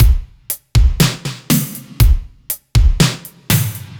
Index of /musicradar/french-house-chillout-samples/120bpm/Beats
FHC_BeatC_120-01.wav